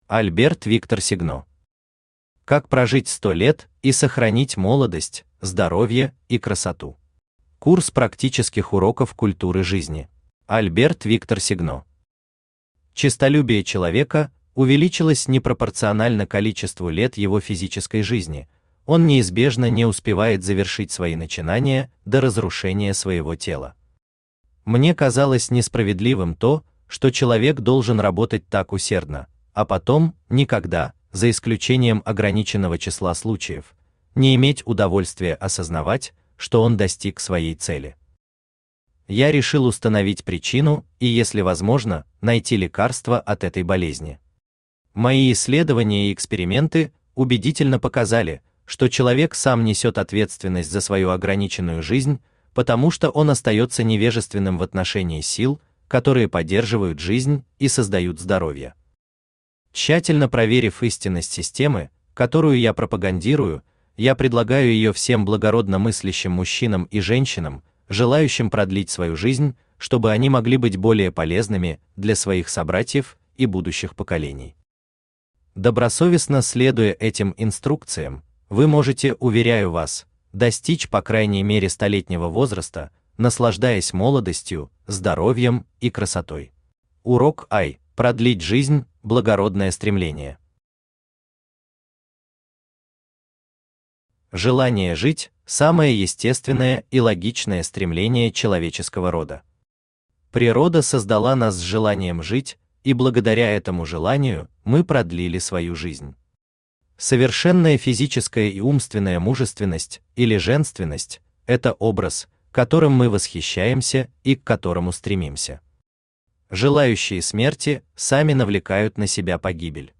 Аудиокнига Как прожить 100 лет и сохранить молодость, здоровье и красоту. Курс практических уроков культуры жизни | Библиотека аудиокниг
Курс практических уроков культуры жизни Автор Альберт Виктор Сегно Читает аудиокнигу Авточтец ЛитРес.